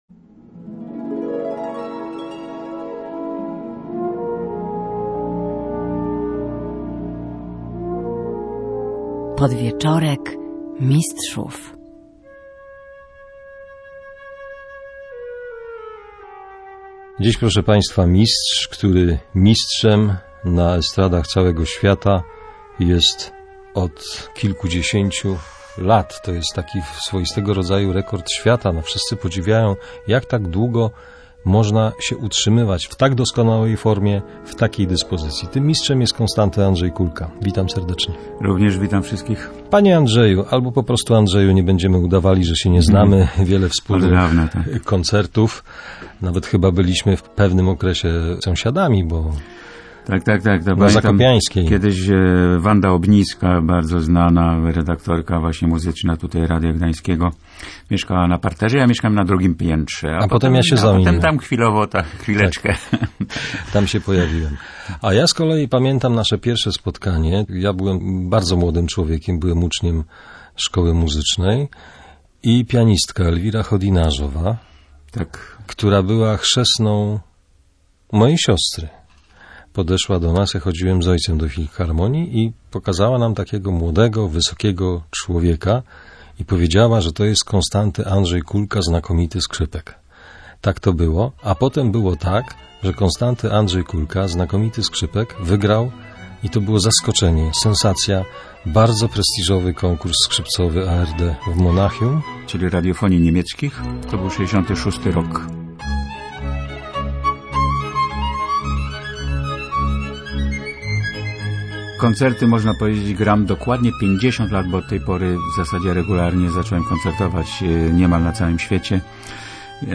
Powtórka rozmowy ze znakomitym skrzypkiem K.A. Kulką, ponieważ jutro maestro otrzyma doktorat honoris causa Akademii Muzycznej im Stanisława Moniuszki.